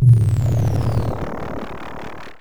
Glitch FX 12.wav